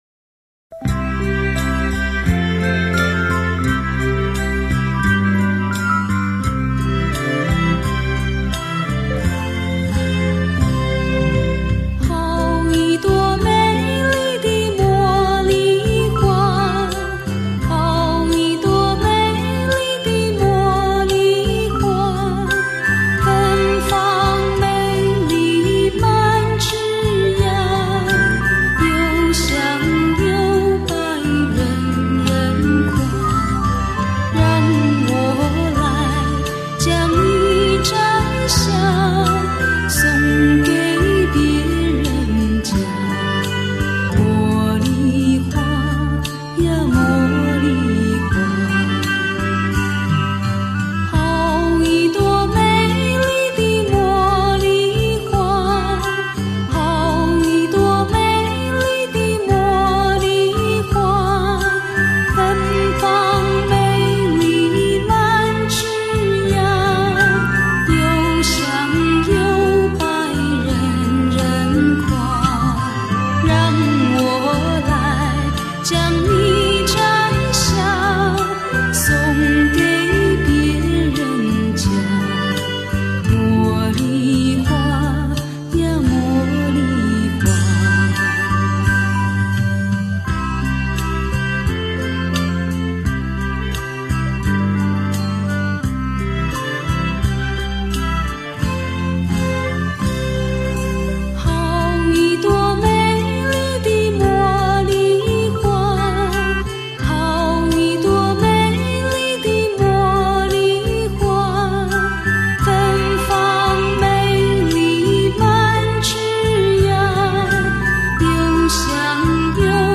Chinese Songs